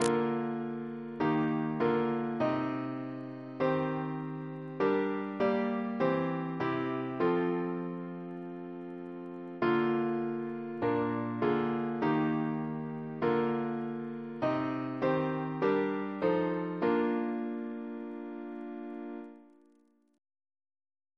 Double chant in B♭ Composer: Henry G. Ley (1887-1962) Reference psalters: ACB: 205